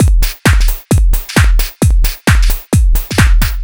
132 Skip N Groove No Perc.wav